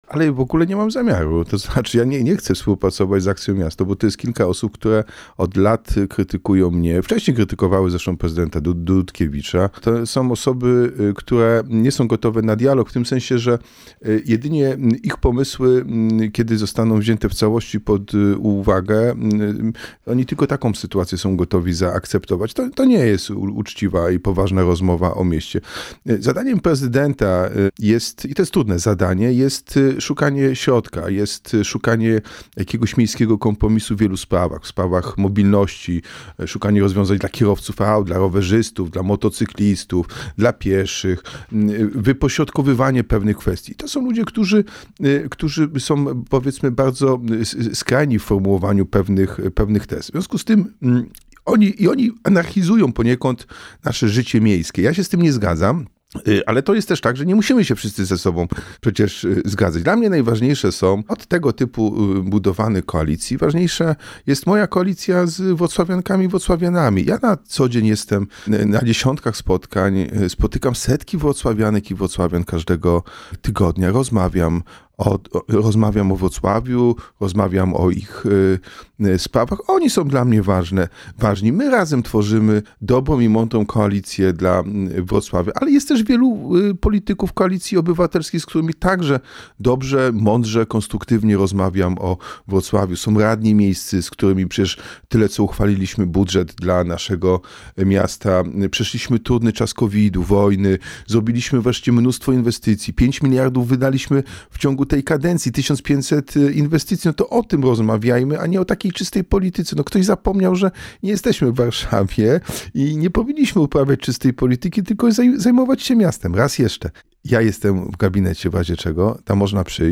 Każdy, kto będzie chciał to porozumienie rozbijać, rozszarpywać, może być winny złego wyniku w wyborach. – mówił w „Porannym Gościu” Jacek Sutryk – prezydent Wrocławia.